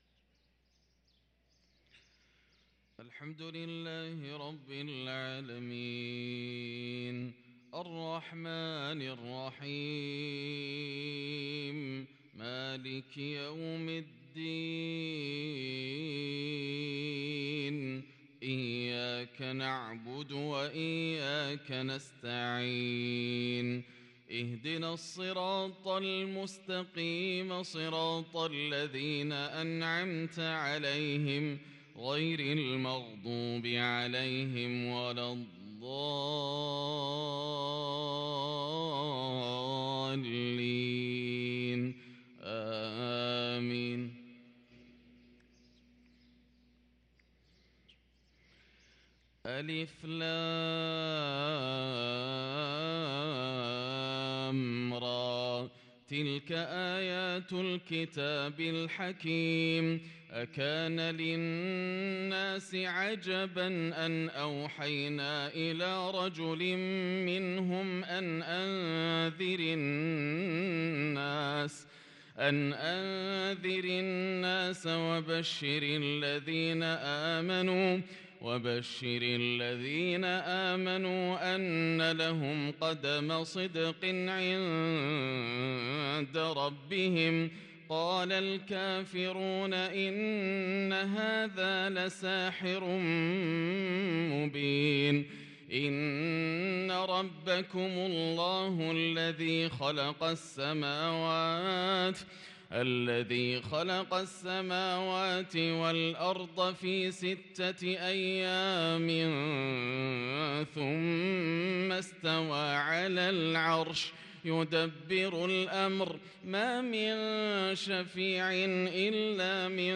صلاة الفجر للقارئ ياسر الدوسري 15 جمادي الآخر 1444 هـ
تِلَاوَات الْحَرَمَيْن .